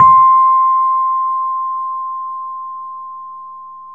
RHODES-C5.wav